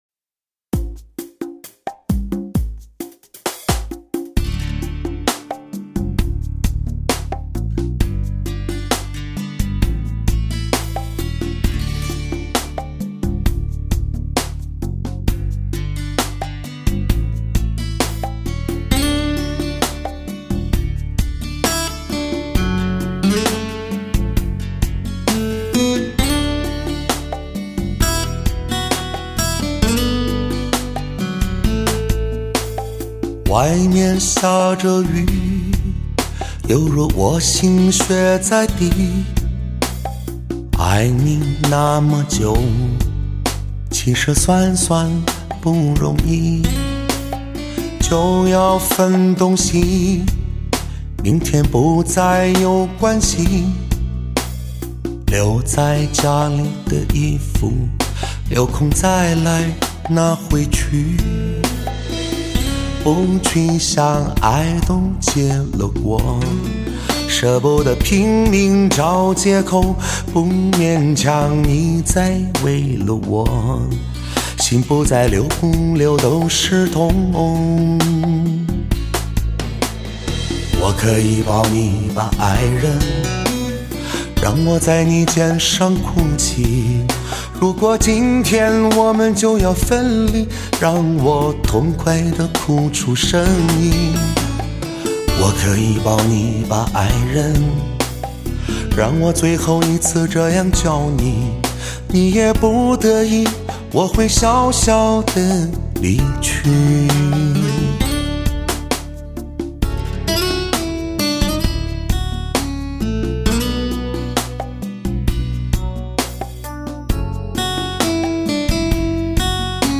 类型: 天籁人声
琴、古筝、风琴等发烧器乐，“非常发烧”的风格再一次突破提升至全新的“专业极限”。